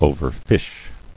[o·ver·fish]